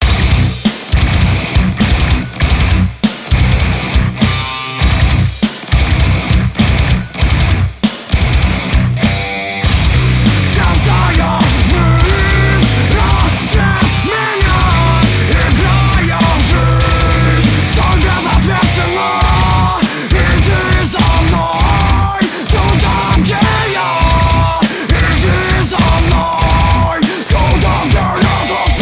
���� ����� - TSE X30/Ozone 4 - Alternative Metal